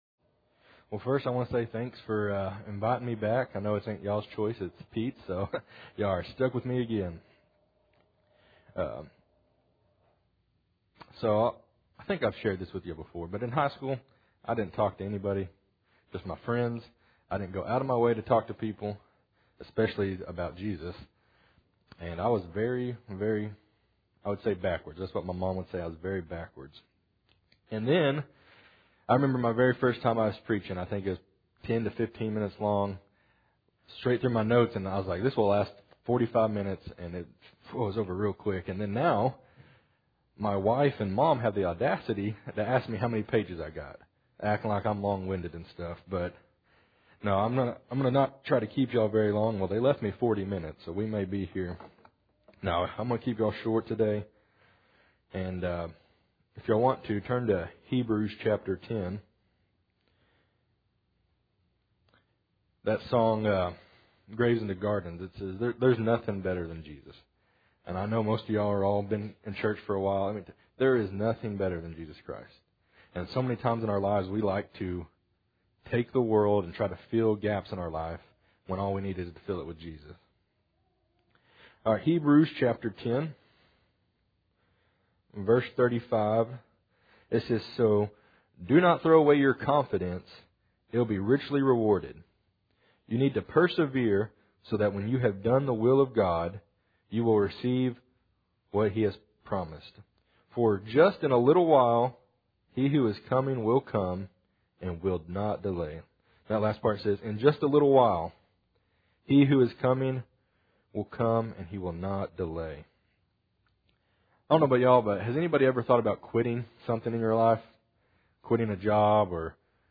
Galatians 6:9 Service Type: Sunday Morning Audio Version Below